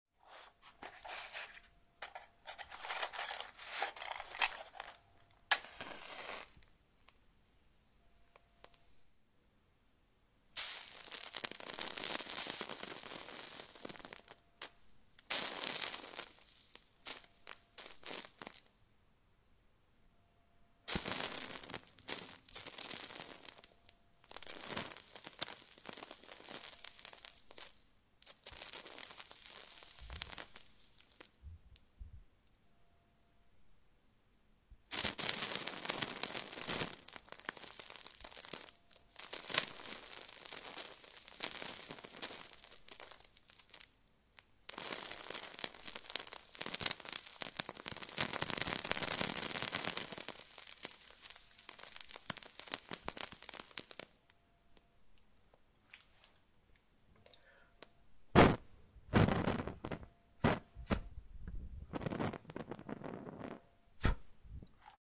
Звуки огня
Звук зажигания свечи треск пламени и шум задувания огня